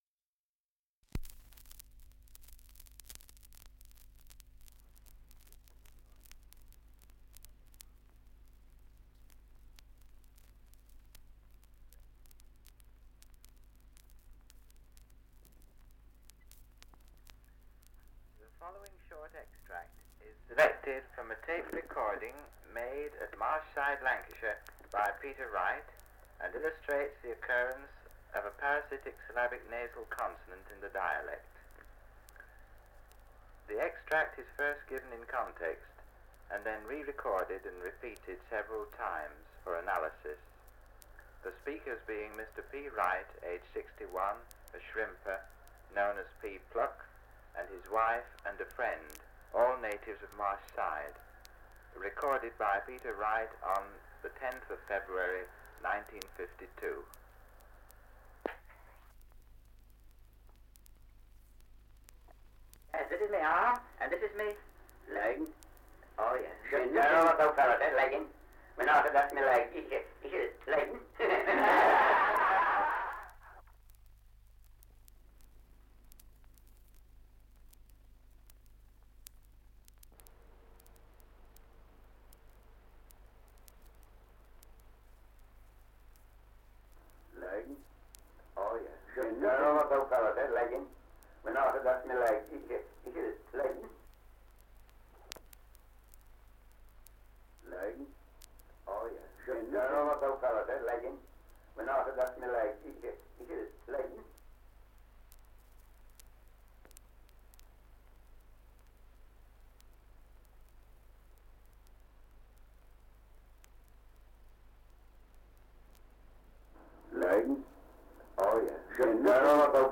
Survey of English Dialects recording in Fleetwood, Lancashire. Survey of English Dialects recording in Marshside, Lancashire
[Side 2] Examples of parasitic nasal on word 'leg' recorded for analysis.
78 r.p.m., cellulose nitrate on aluminium